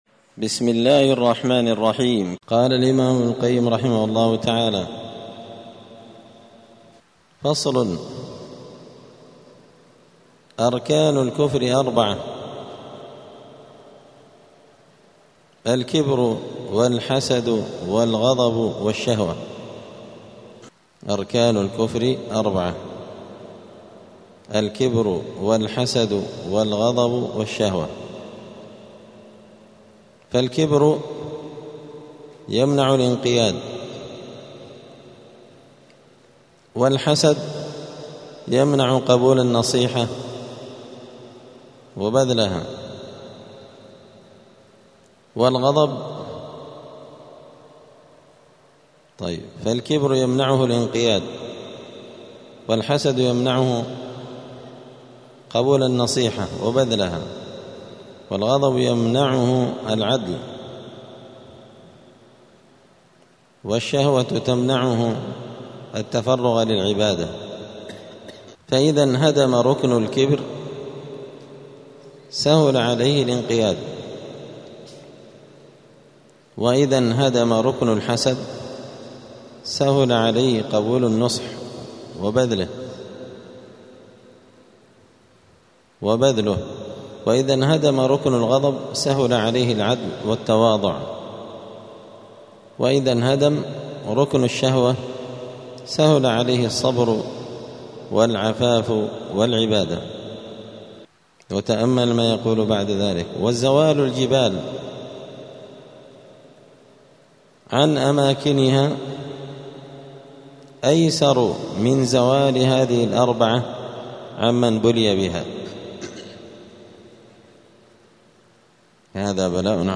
*الدرس الواحد والتسعون (91) {فصل أركان الكفر أربعة}*